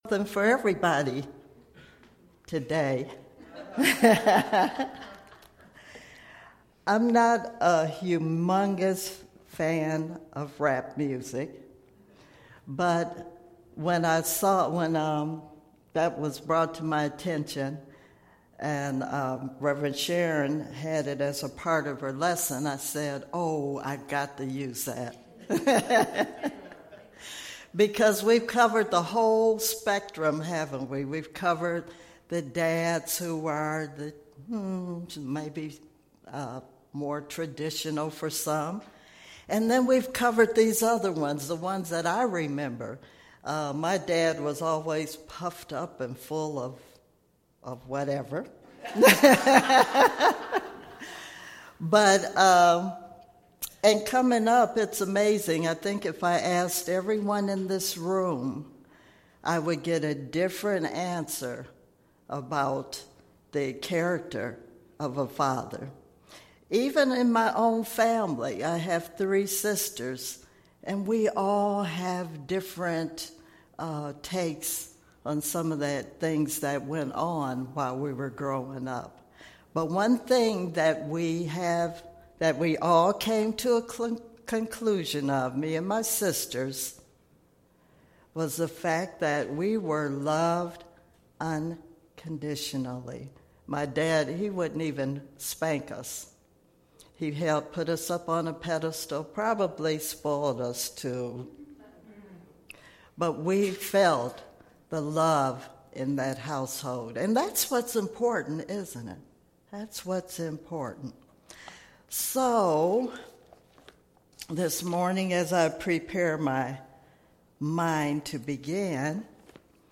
Series: Sermons 2015